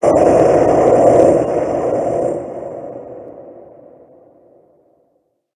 Cri d'Ectoplasma Gigamax dans Pokémon HOME.
Cri_0094_Gigamax_HOME.ogg